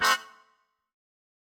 GS_MuteHorn-Cdim.wav